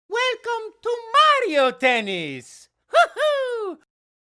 "Welcome to Mario Tennis! Hoo Hoo!" - Mario (voiced by Charles Martinet). Plays on the disc channel of New Play Control! Mario Power Tennis.